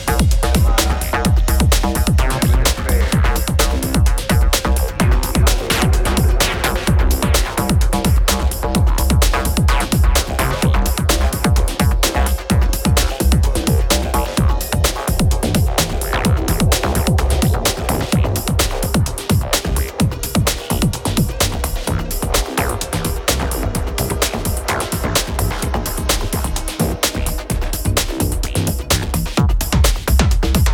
Electro Electronix House Techno Breaks Trance